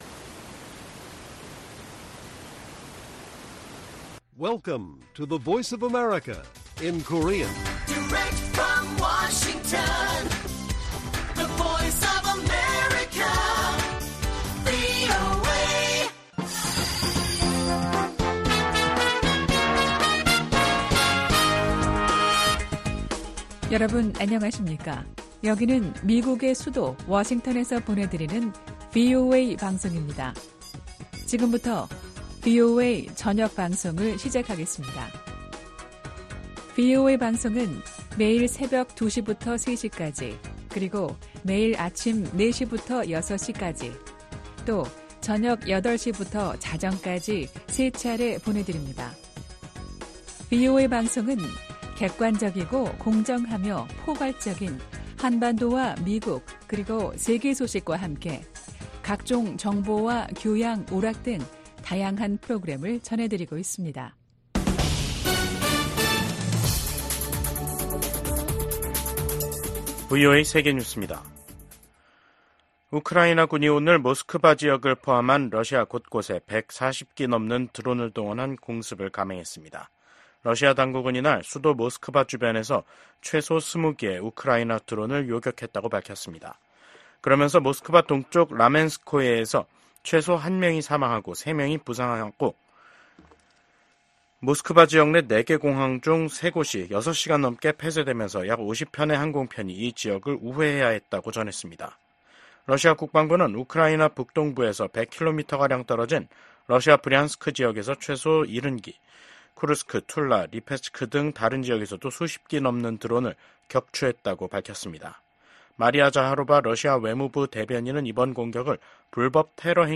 VOA 한국어 간판 뉴스 프로그램 '뉴스 투데이', 2024년 9월 10일 1부 방송입니다. 민주당 대통령 후보인 카멀라 해리스 부통령이 당선되면 동맹을 강화하며 국제 지도력을 발휘할 것이라는 입장을 재확인했습니다. 북한 사립대학 외국인 교수진의 복귀 소식에 미국 국무부는 미국인의 ‘북한 여행 금지’ 규정을 상기했습니다. 김정은 북한 국무위원장이 9.9절을 맞아 미국의 핵 위협을 주장하며 자신들의 핵 무력을 한계 없이 늘려나가겠다고 밝혔습니다.